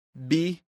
Výslovnost a pravopis